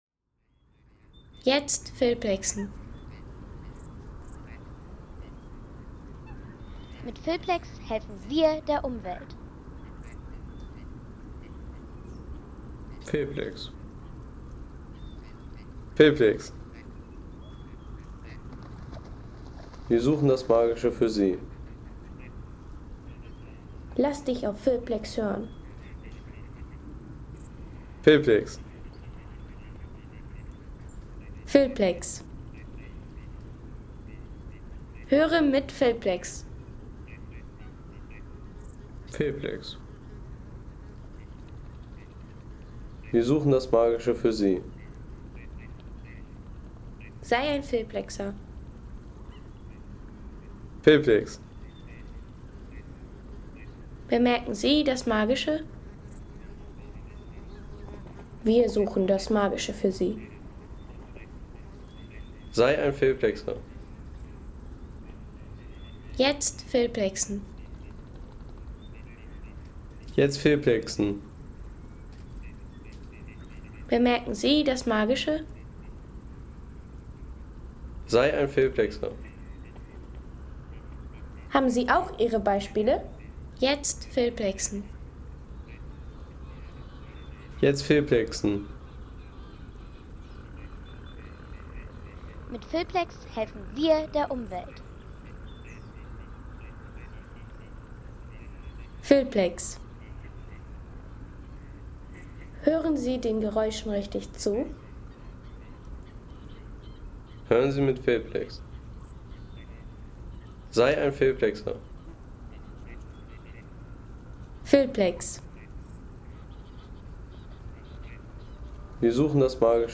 Ein Seesound, der auf die Dämmerung gewartet hat
Atmosphärischer See-Sound vom Großen Segeberger See in der Dämmerung mit ruhiger Uferstimmung und natürlicher Abendkulisse.
Natürliche Abendatmosphäre vom Großen Segeberger See mit stillen Schilfufern, ruhiger Landschaft und friedlichem Seeflair.